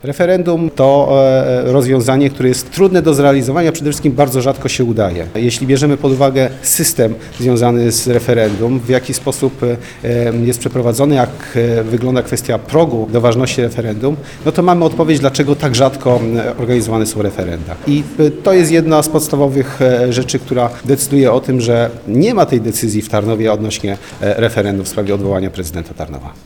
Z takim pomysłem nie zgadza się opozycja, która tuż przed sesją zaprosiła dziennikarzy na konferencję prasową. Przewodniczący klubu radnych PiS Mirosław Biedroń, mówił, że problemami ze zbilansowaniem budżetu nie można obciążać portfeli tarnowian.